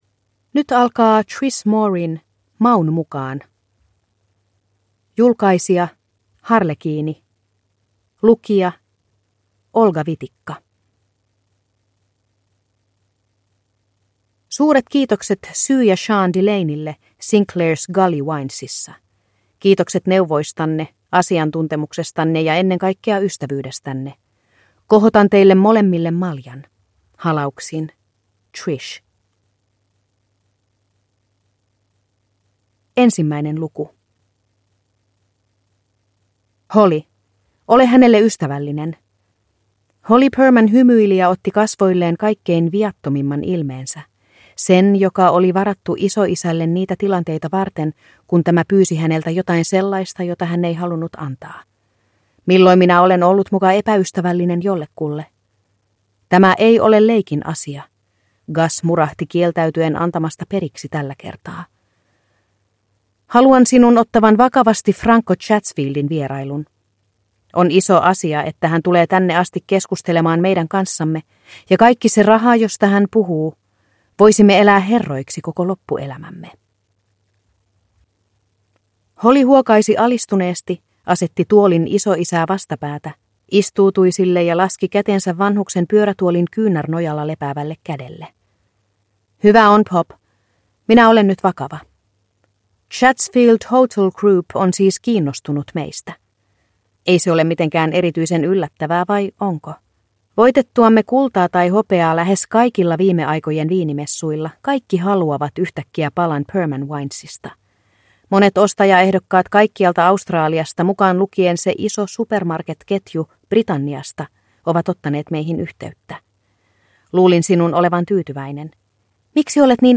Maun mukaan (ljudbok) av Trish Morey